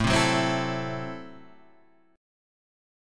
Am6 chord {x 0 2 2 1 2} chord